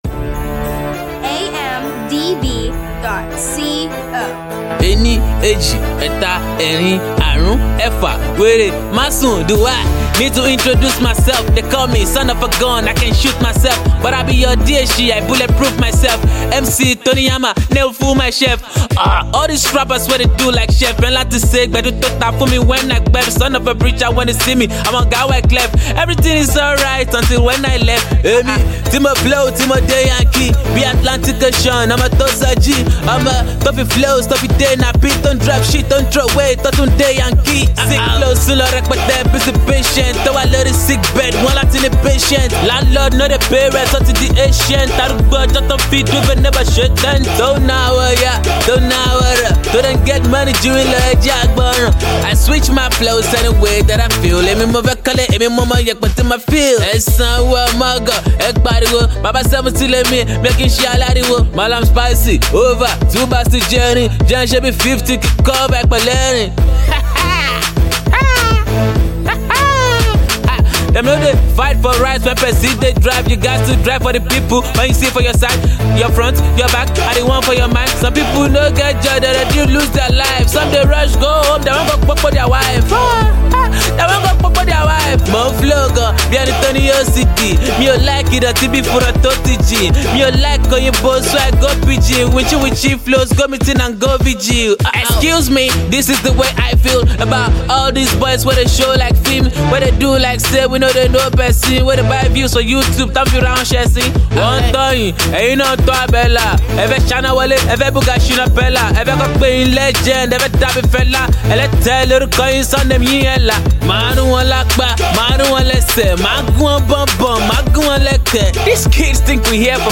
is a Nigerian hip hop recording artist from Bariga